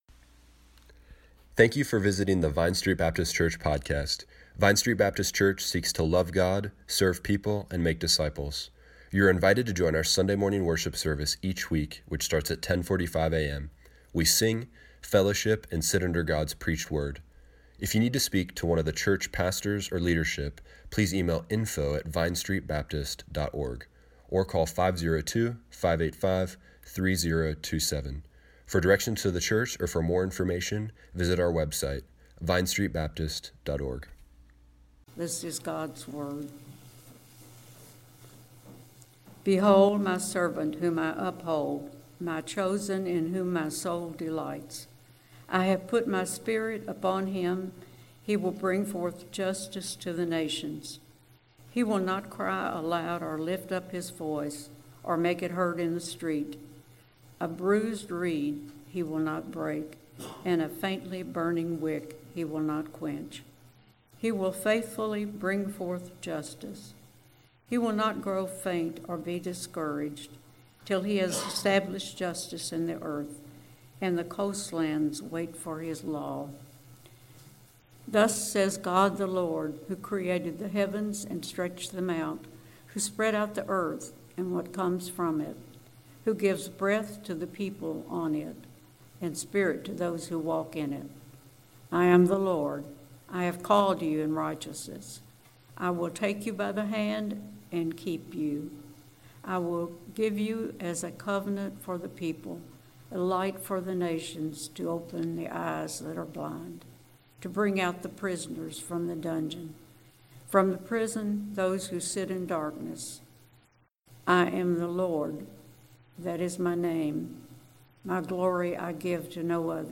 Today’s sermon continues the sermon series Fulfilled, an Advent Series.